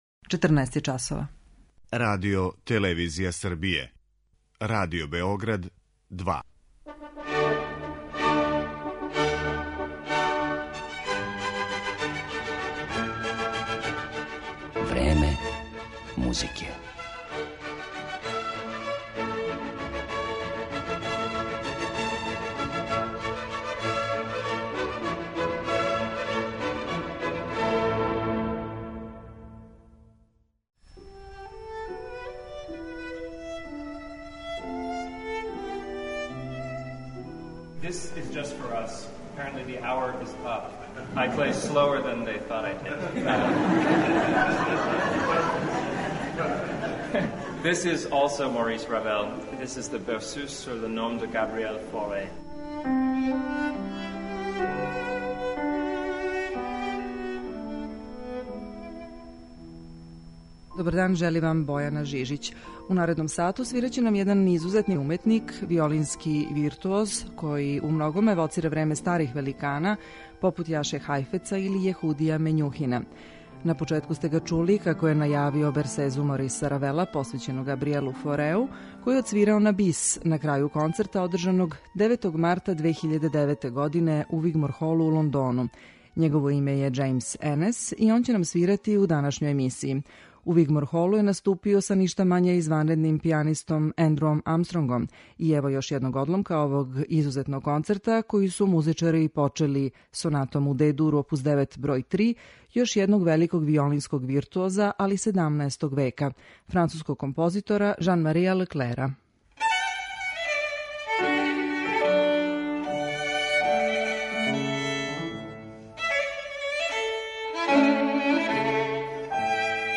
Изузетни виолински виртуоз Џејмс Енез својим стилом свирања евоцира време старих великана попут Јаше Хајфеца или Јехудија Мењухина.
Овај интригантни канадски солиста ће изводити композиције Мориса Равела, Сергеја Прокофјева, Виљема Волтона и Јохана Себастијана Баха.